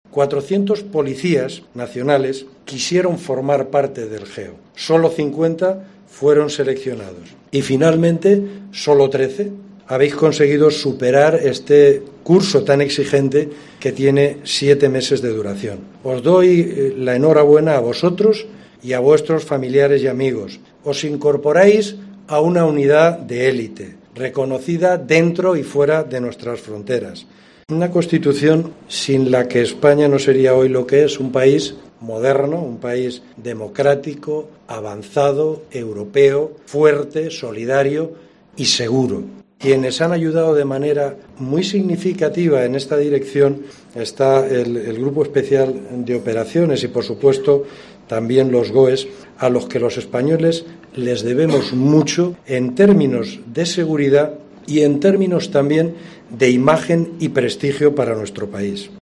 Son las palabras del Director General de la Policía, Francisco Pardo, que esta mañana ha visitado oficialmente por primera vez el cuartel GEO en Guadalajara
Hoy se ha celebrado la clausura del Vigésimo-noveno Curso del GEO, en el cuartel que tiene este Grupo Especial en Guadalajara.